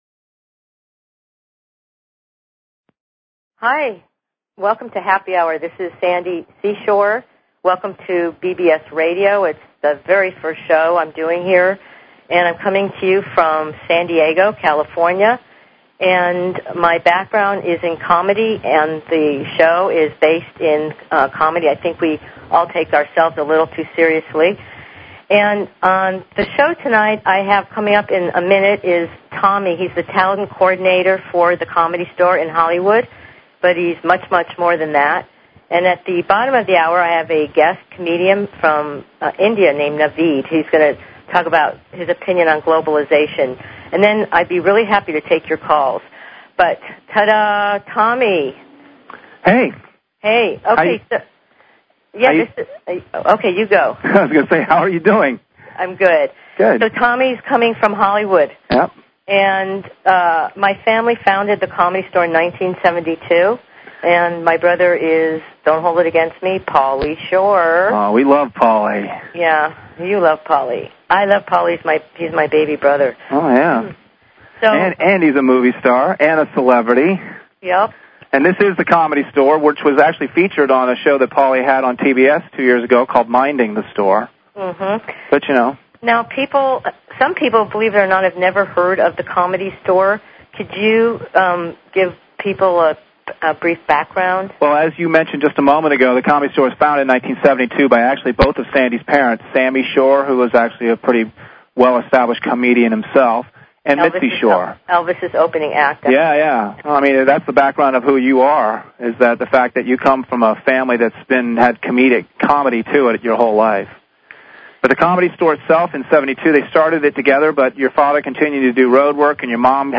Talk Show Episode, Audio Podcast, Happy_Hour_Radio and Courtesy of BBS Radio on , show guests , about , categorized as